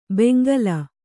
♪ bengala